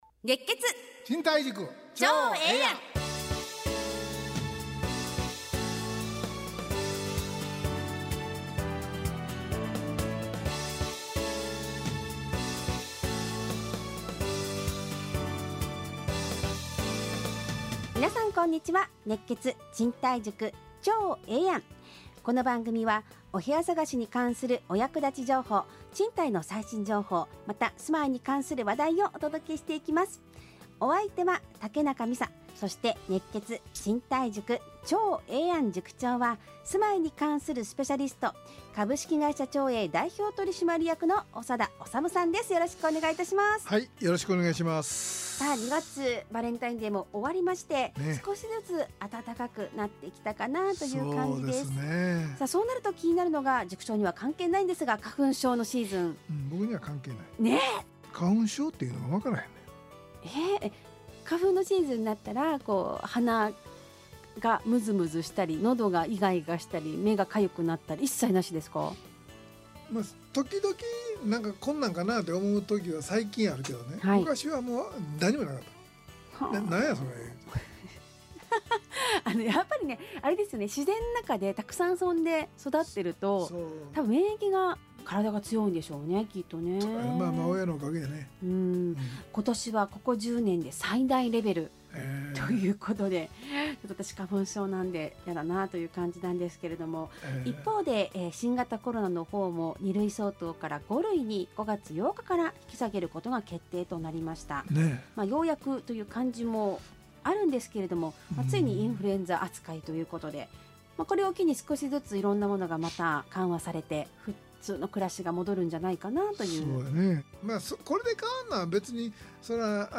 ラジオ放送 2023-02-17 熱血！